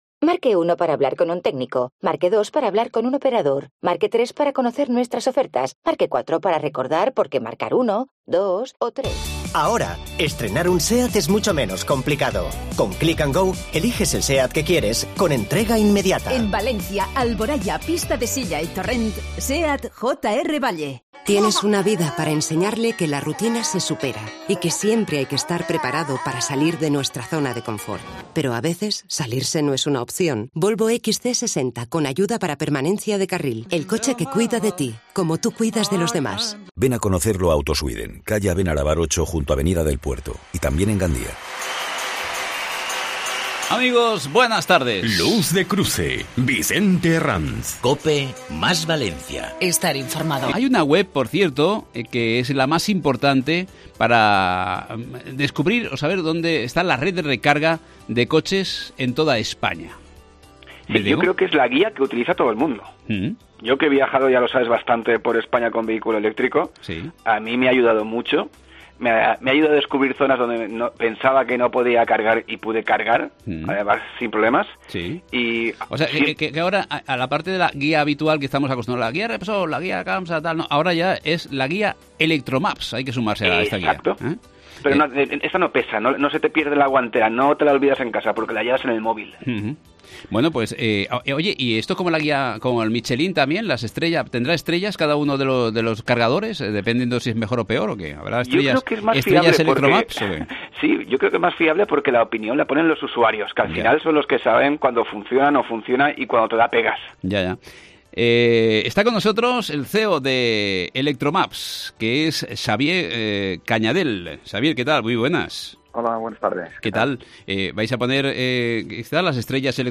Luz de cruce ENTREVISTA